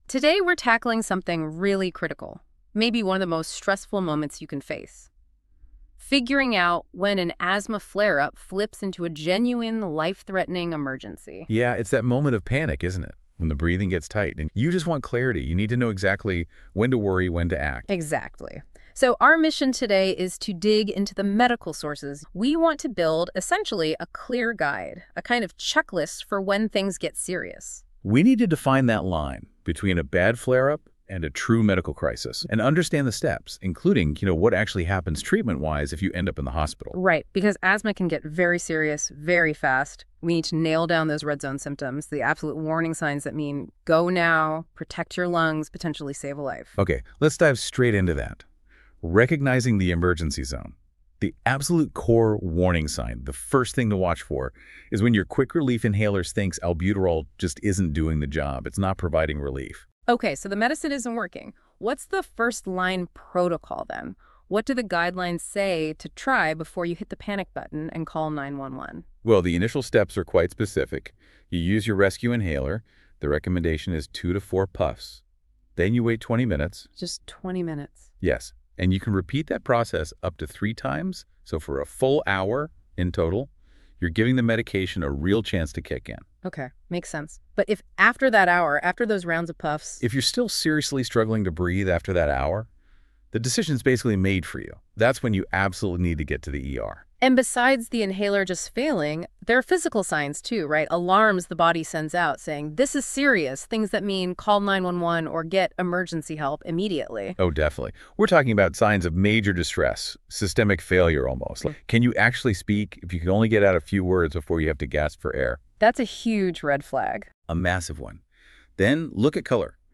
Listen to a discussion on when to go to the hospital for asthma When to go to the hospital ER for asthma If you’re having trouble breathing and your quick-relief inhaler (like albuterol) isn’t working for you, it’s time to get emergency help.